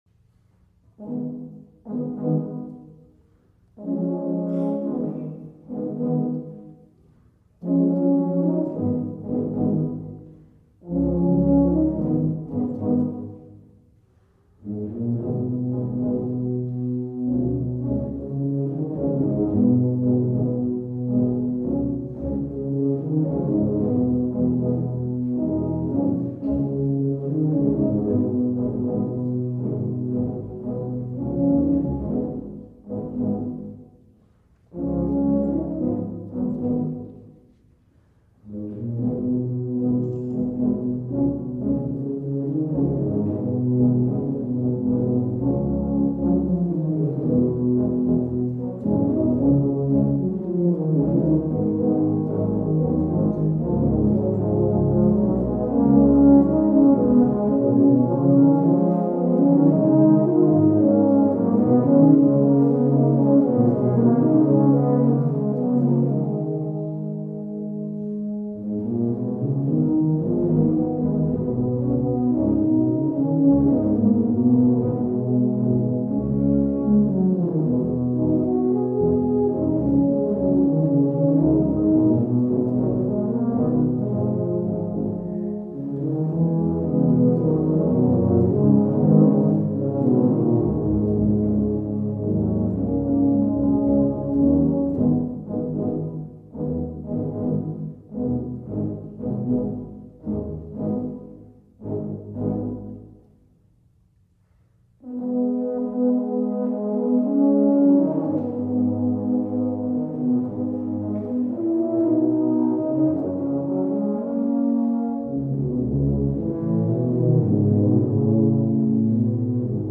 Jazz. Easy blues.